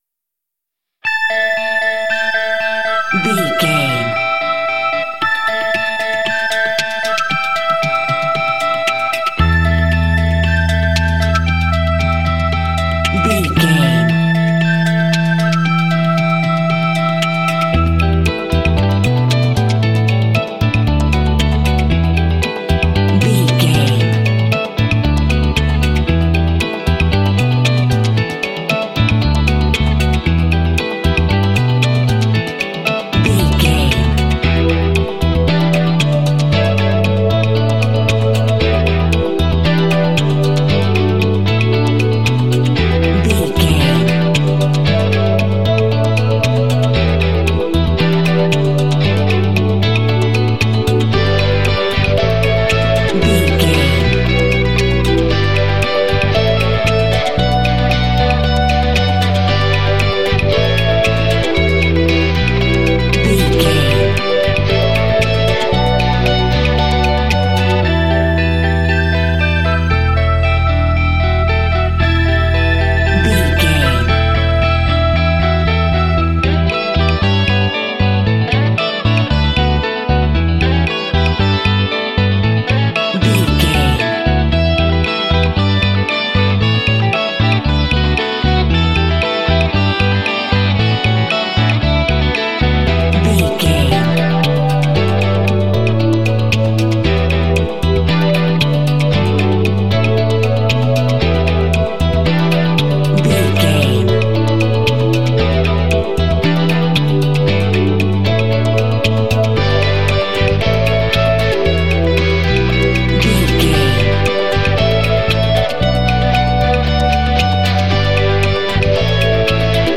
Dorian
cool
happy
groovy
bright
electric guitar
strings
bass guitar
synthesiser
percussion
rock
alternative rock
symphonic rock